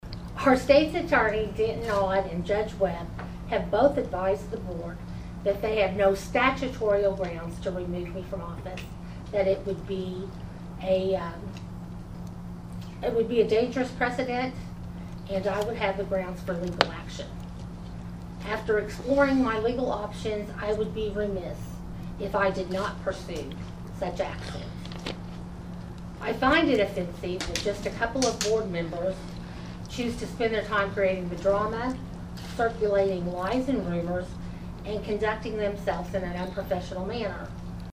Over the next hour, only one individual abided by that request and it was mostly a group conversation that resembled Great Britain’s parliamentary procedures with interruptions and occasional raised voices.